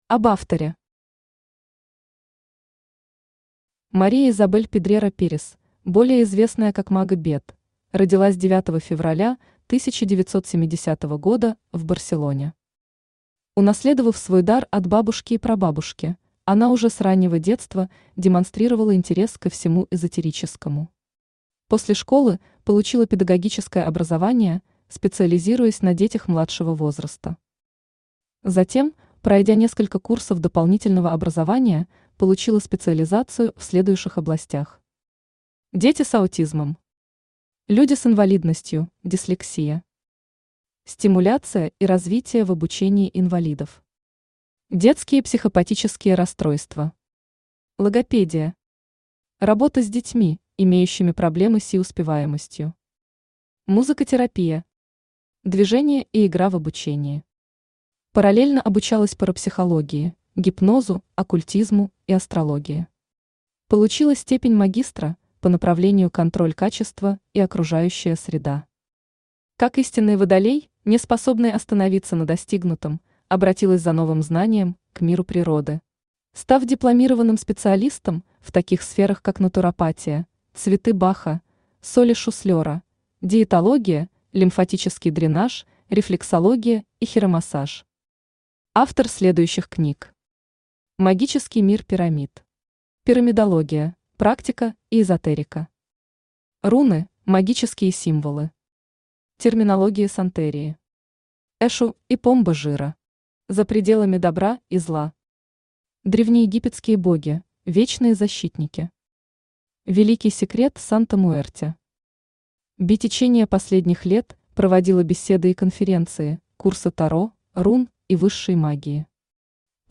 Аудиокнига Мир Эшу и Помба Жира | Библиотека аудиокниг
Aудиокнига Мир Эшу и Помба Жира Автор Maribel Pedrera Pérez – Maga Beth Читает аудиокнигу Авточтец ЛитРес.